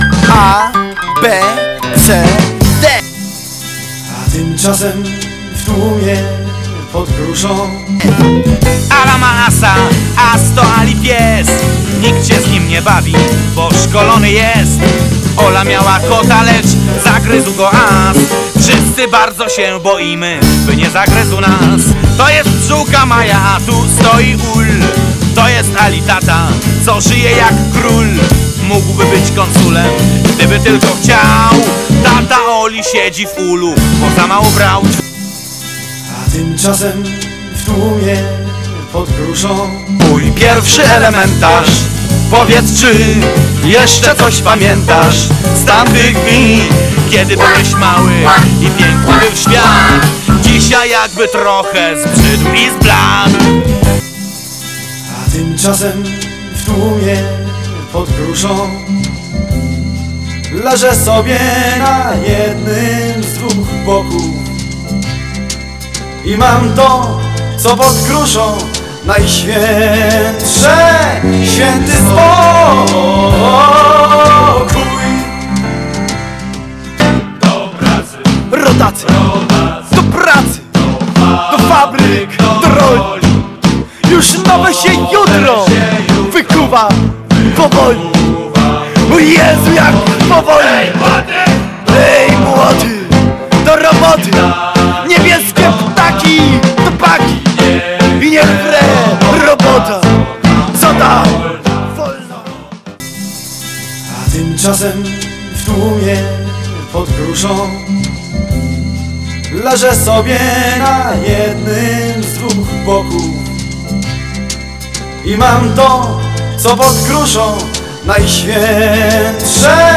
Mikst 5minut z hitów